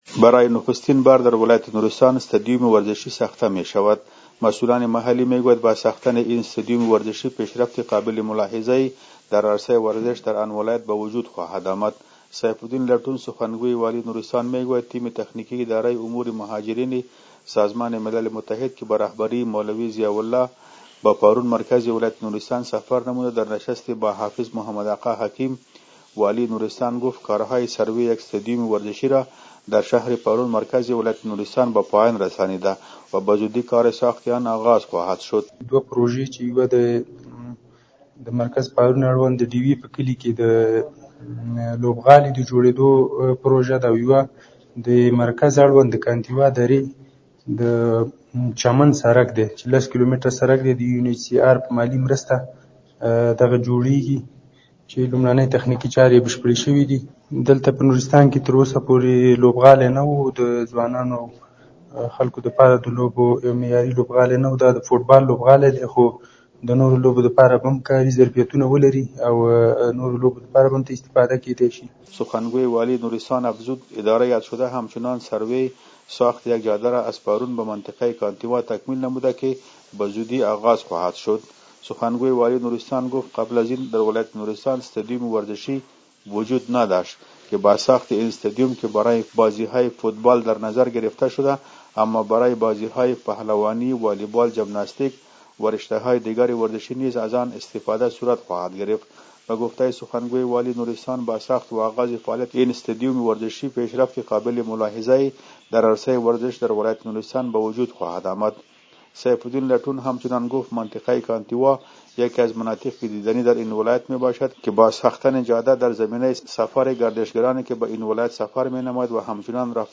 خبر / ورزشی